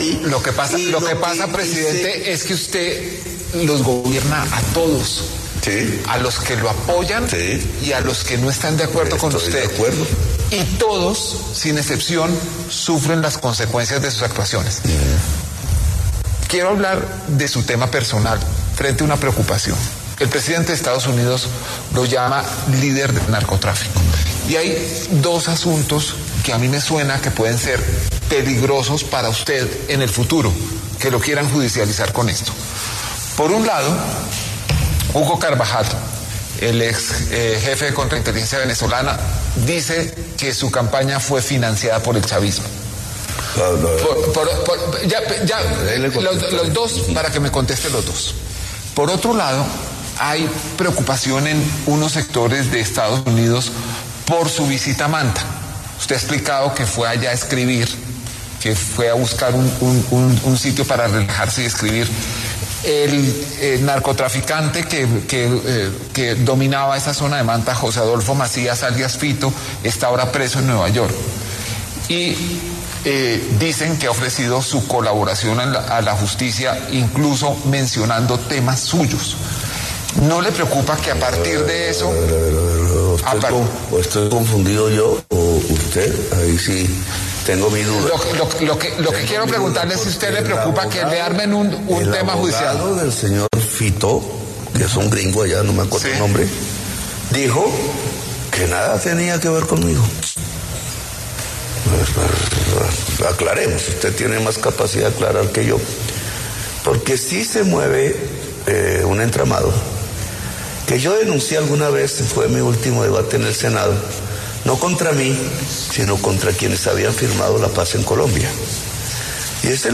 En diálogo con Daniel Coronell, Gustavo Petro agregó que Néstor Humberto Martínez “usó el conflicto Farc-Estado para hacer plata”.